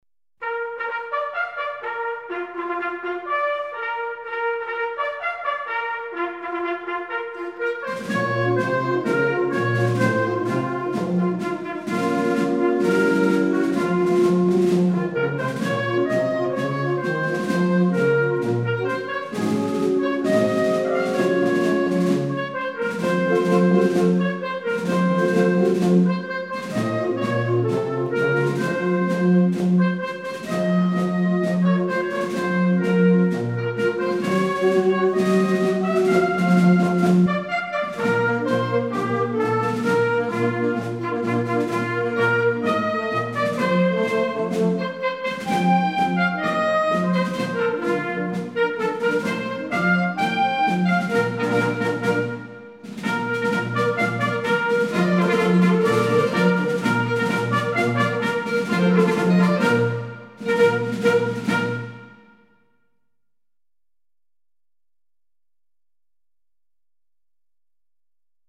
La Protestation des Chasseurs (sonnée)